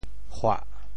How to say the words 嚯 in Teochew？
嚯 Radical and Phonetic Radical 口 Total Number of Strokes 19 Number of Strokes 16 Mandarin Reading huò TeoChew Phonetic TeoThew hua7 文 Chinese Definitions 嚄〈叹〉 表示惊讶 [ah]。
hua7.mp3